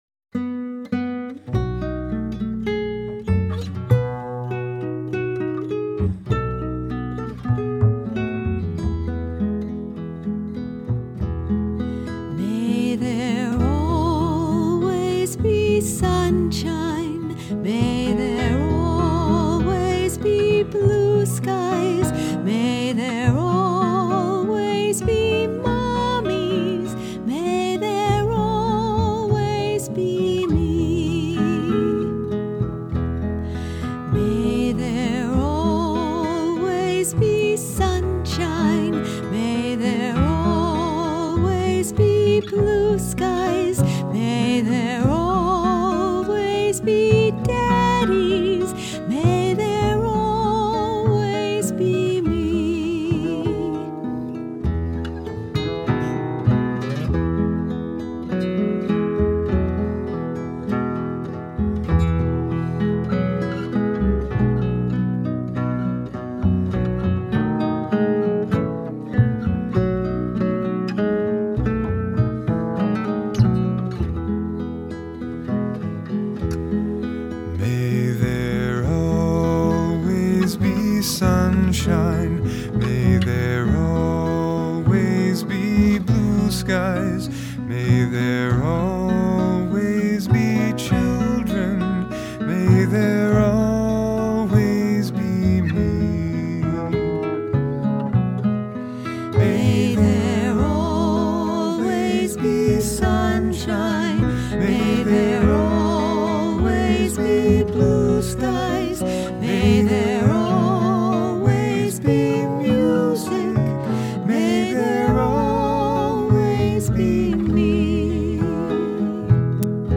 Russian Folk Song, натурально.
Один сплошной припев :-)